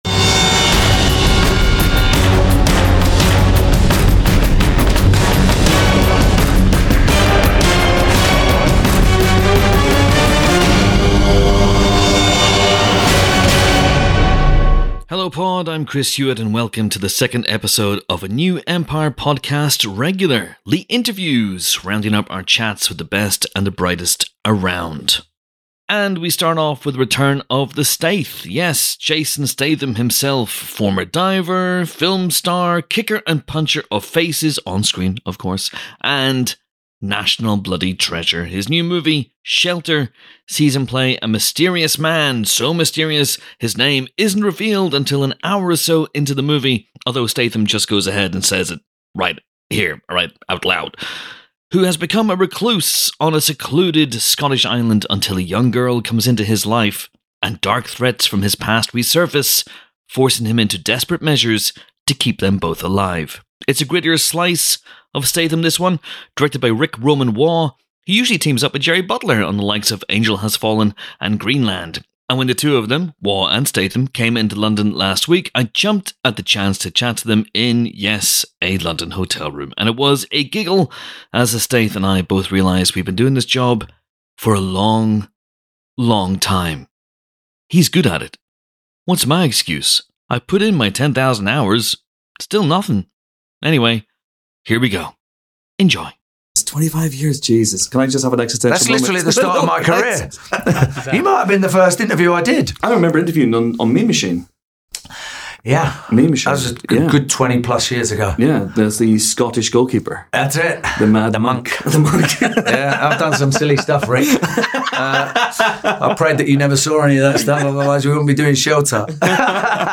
jan_30_interviews_special_mixdow_d0fe7fe5_normal.mp3